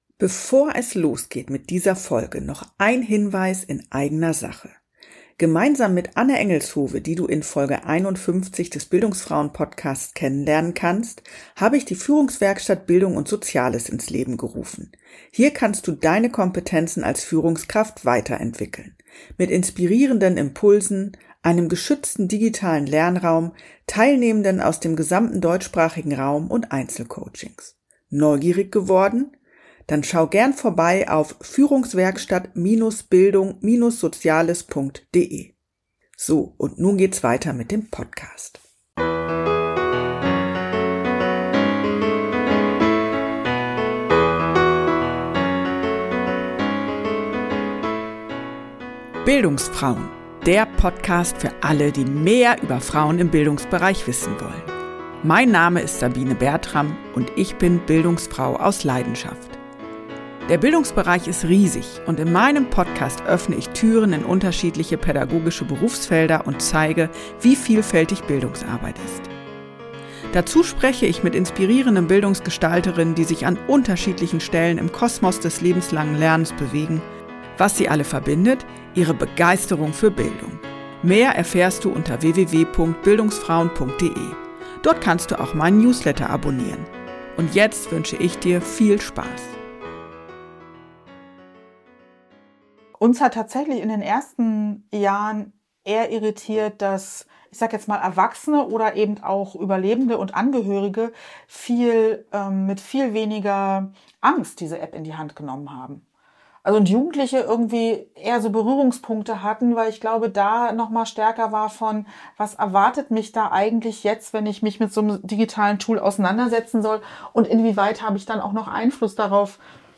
Viel Spaß mit unserem Gespräch!